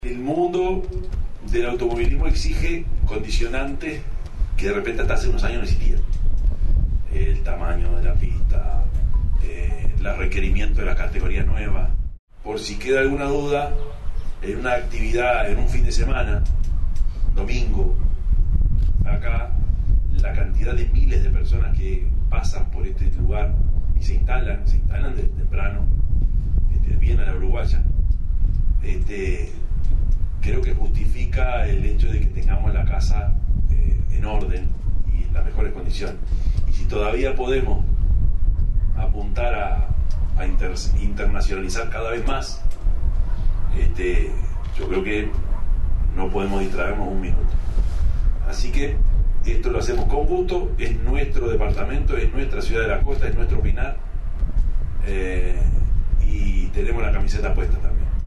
Se realizó la firma de convenio entre la Intendencia de Canelones y la Asociación Uruguaya de Volantes (AUVO) en el Autódromo Víctor Borrat Fabini de El Pinar en Ciudad de la Costa, cuyo objetivo es permitir el desarrollo de las obras de mantenimiento y ampliación del autódromo y las áreas circundantes.
yamandu_orsi_-_intendente_de_canelones_2.mp3